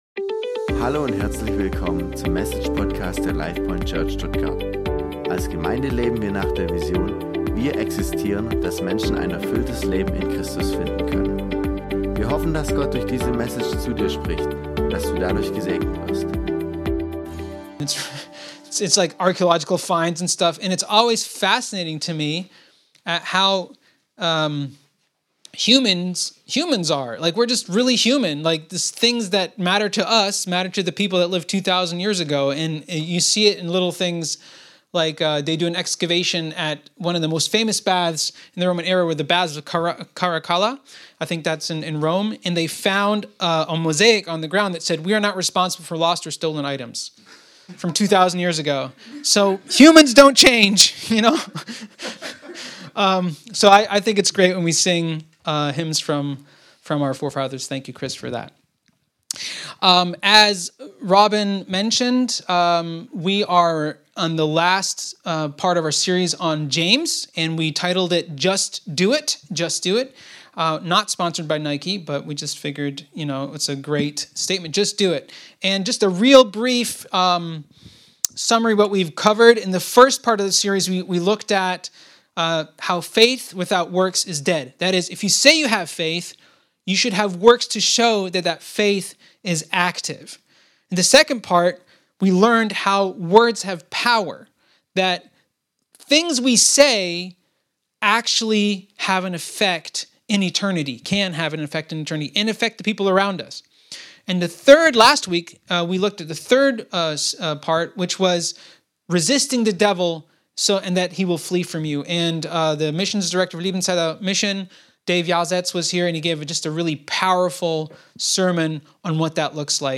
The sermon motivates to use prayer as a spiritual weapon and shield to face challenges and seek God's guidance in everyday life.